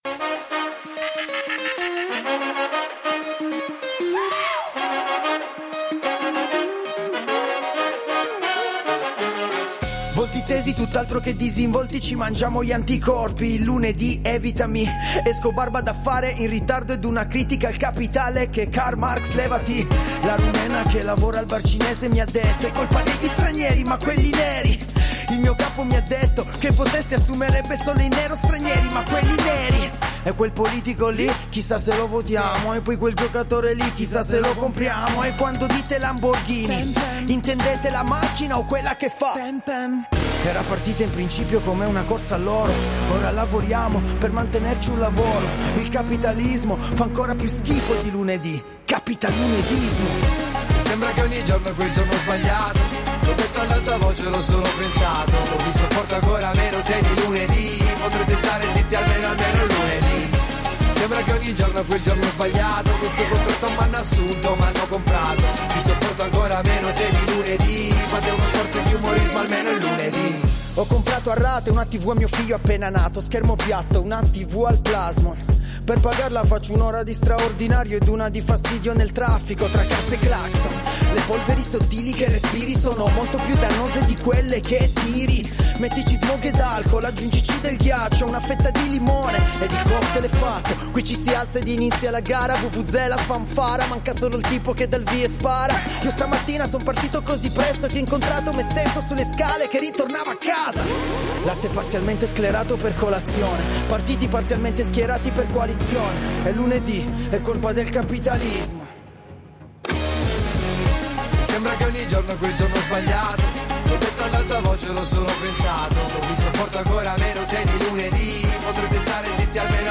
Intervista
Intervista telefonica